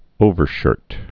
(ōvər-shûrt)